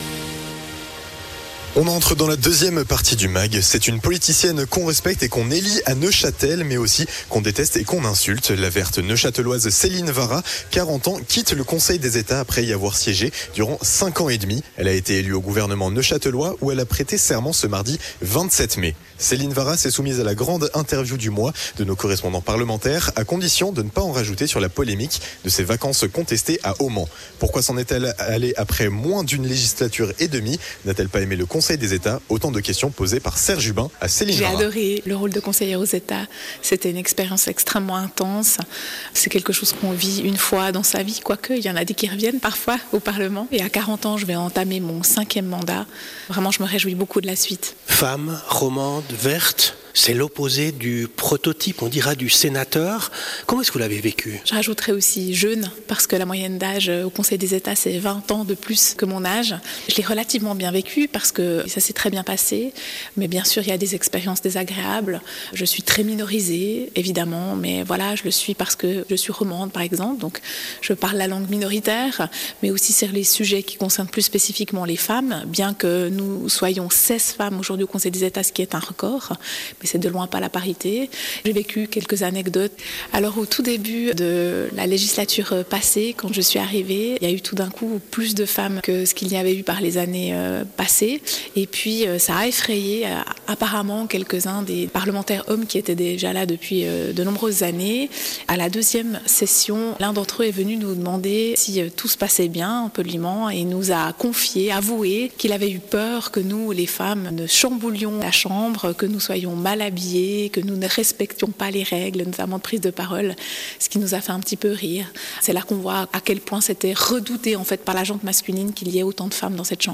Grande interview de nos correspondants parlementaires à Berne: Céline Vara